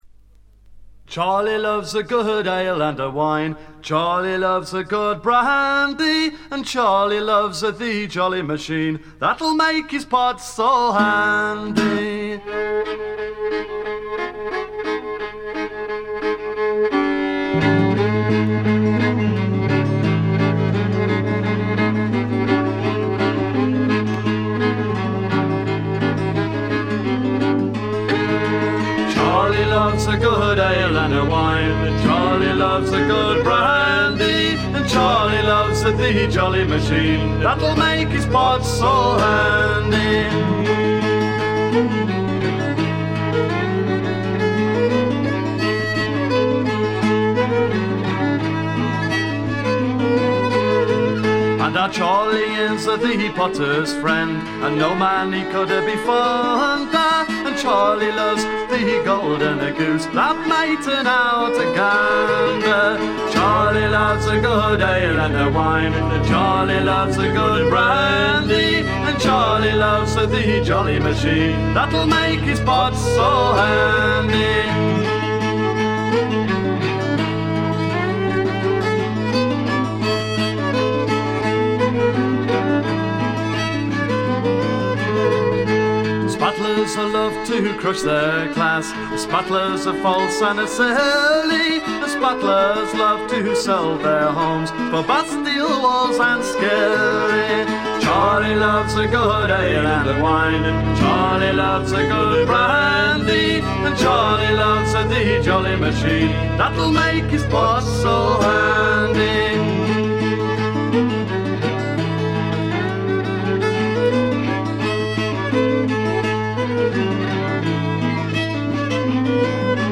レア度、内容ともに絶品のフィメールトラッドフォークです。
試聴曲は現品からの取り込み音源です。